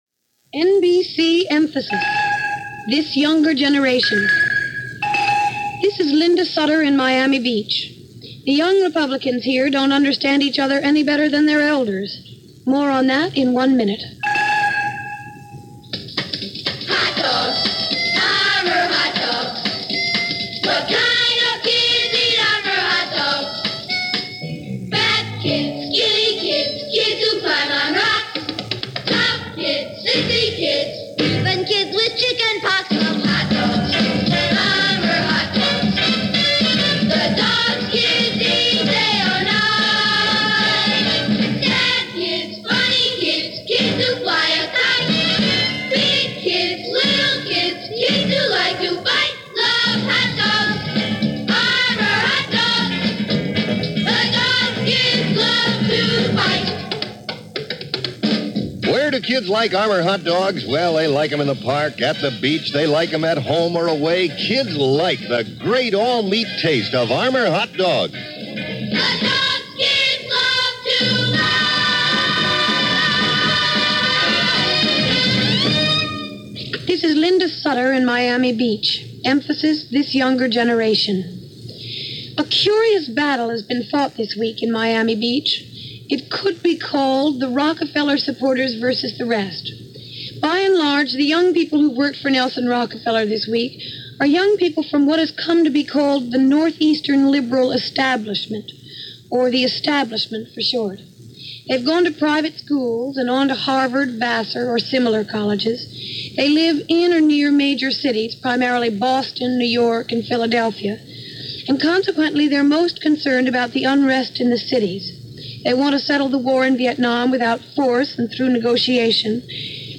Here is a report, via NBC Radio’s Emphasis series, from August 10, 1968, while the Republican Convention was taking place in Miami, on the subject of Rockefeller and the Youth Vote.